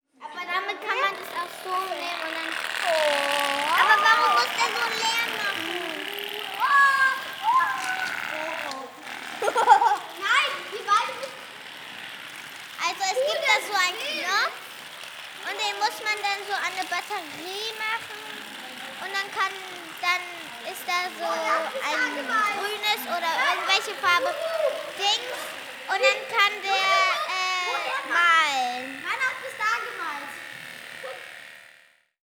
Fostex FR-2LE & beyerdynamic mc 82
ATMO
Atmo_Fostex FR-2LE_Beyerdynamic MC82.wav